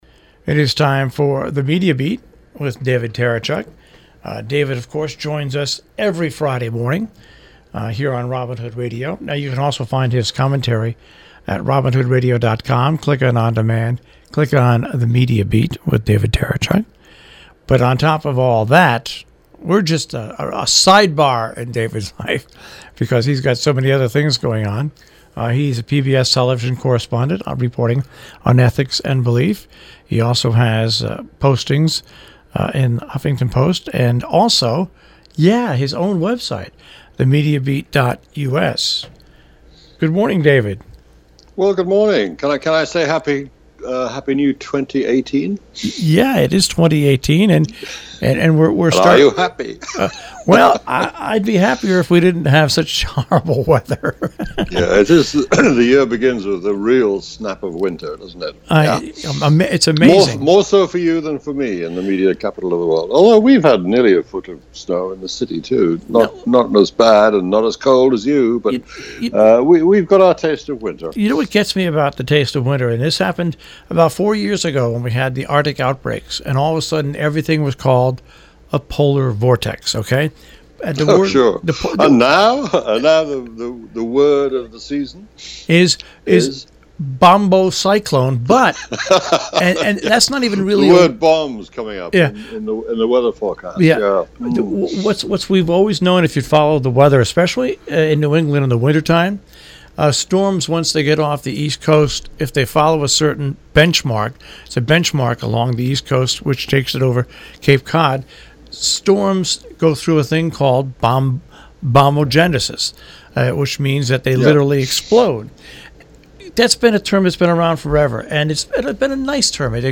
A radio version of THE MEDIA BEAT appears every week on the NPR Connecticut station WHDD – live on Friday morning and rebroadcast over the weekend.